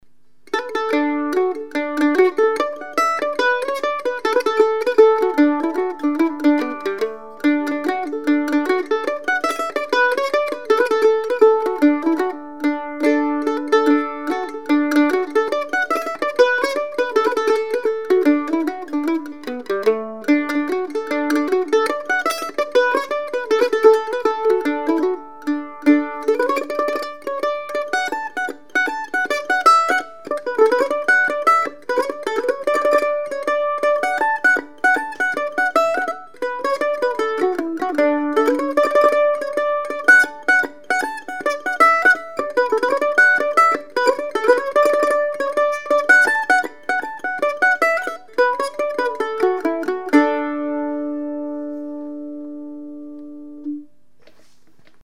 2 Point A5 Mandolin #342  $5200 (includes case)
Sweet, responsive and very evenly balanced, and it does like the Thomastic strings.  Beautiful sound when played soft, but can really push out the volume when hit hard.